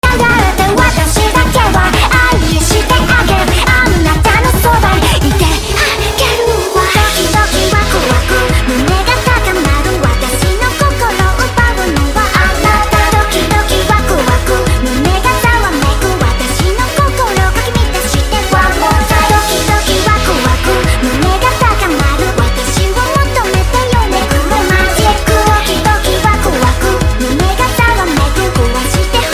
It's just a nightcore version of the original.